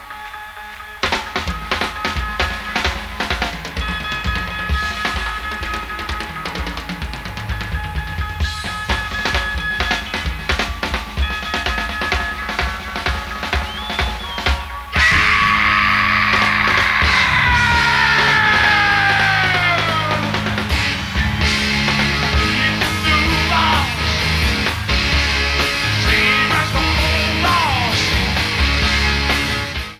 Pre-FM Radio Station Reels
Needs remastering.
Drum Solo